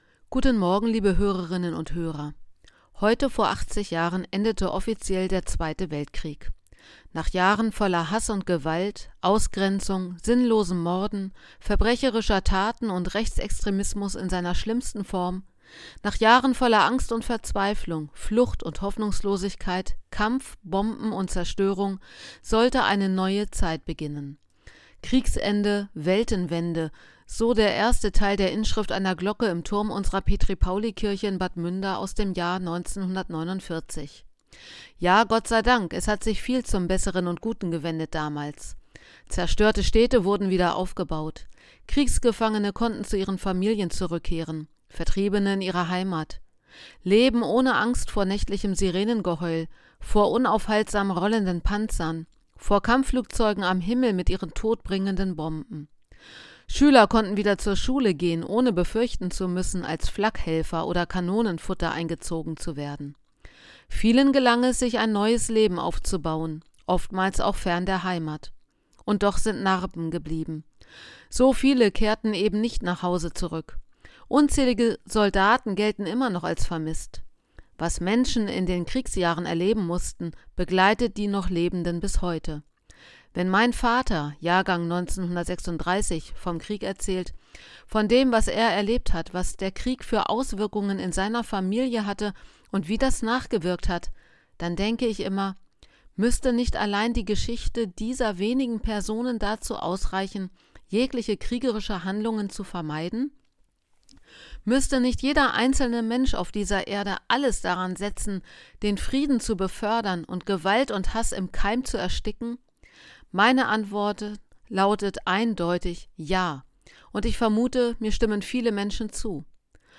Radioandacht vom 8. Mai